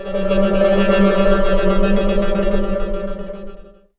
Abstract Texture
An evolving abstract sonic texture with granular particles and shifting tonal movement
abstract-texture.mp3